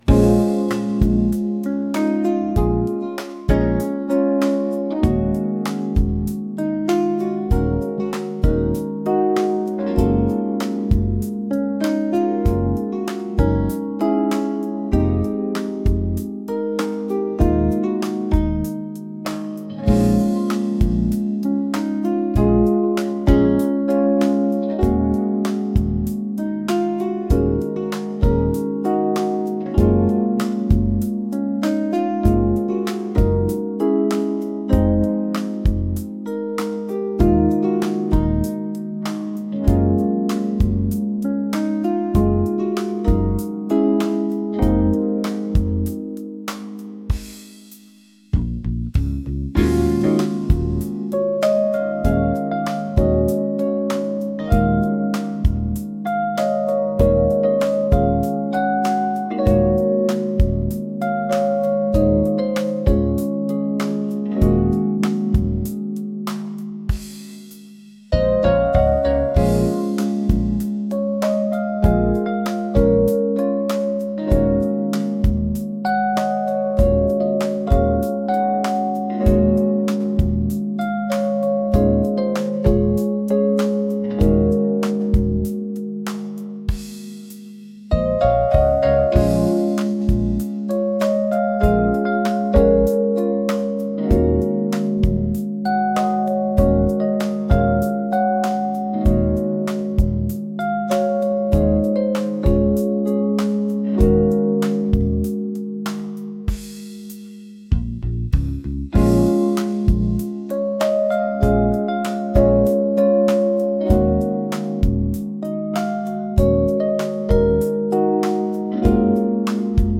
soulful | fusion | mellow